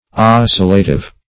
Search Result for " oscillative" : The Collaborative International Dictionary of English v.0.48: Oscillative \Os"cil*la*tive\, a. Tending to oscillate; vibratory.